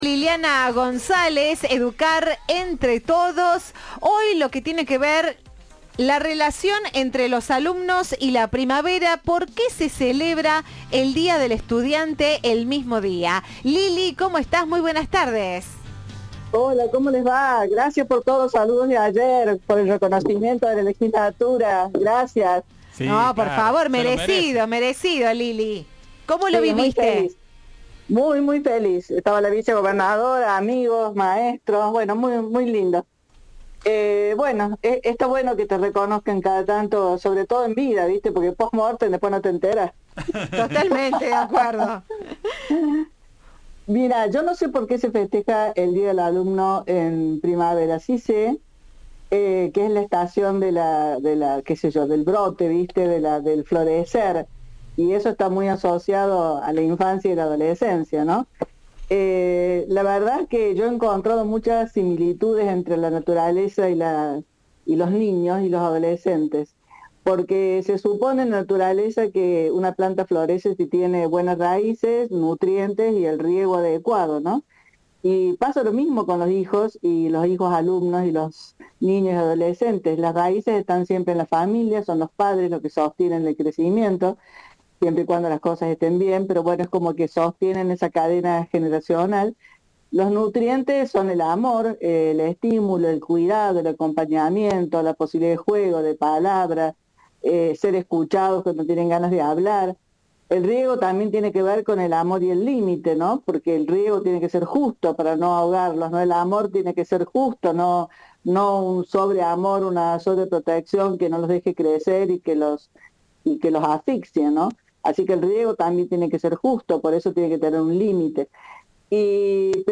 Entrevista de Viva la Radio